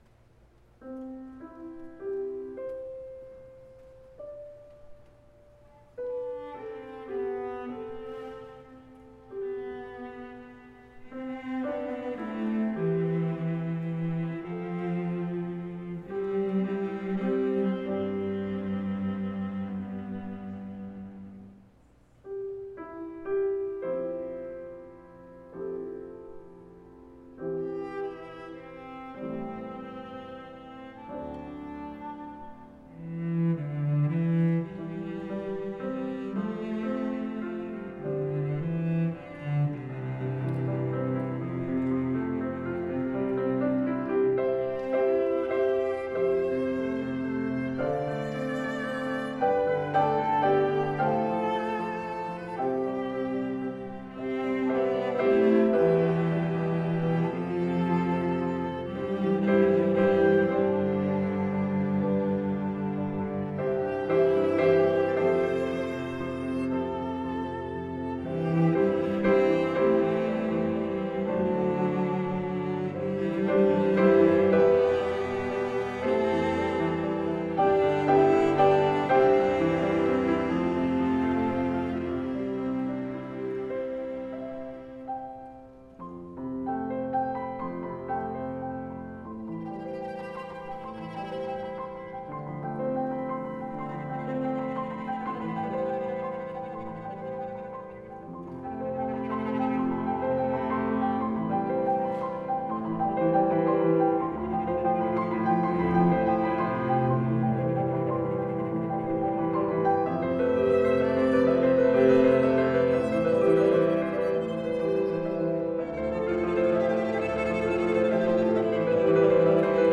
for Viola, Cello, and Piano (2021)